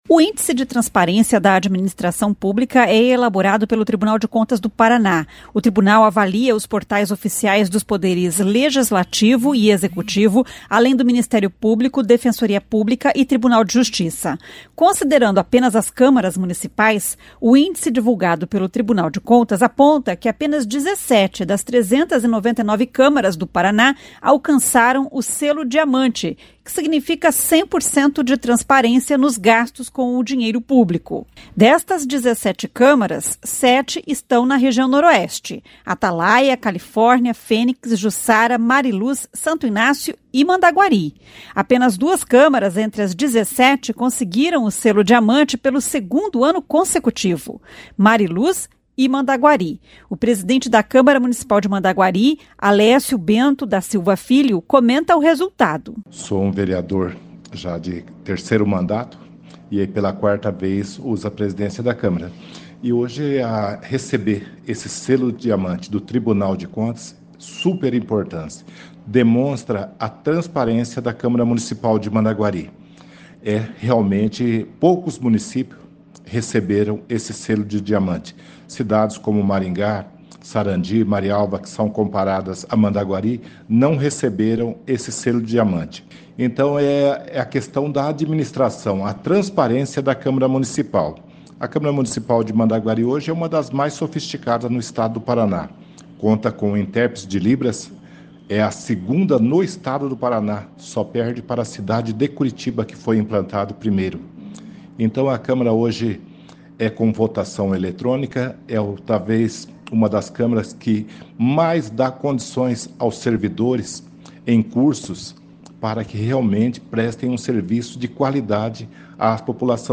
O presidente da Câmara Municipal de Mandaguari, Alécio Bento da Silva Filho, comenta o resultado.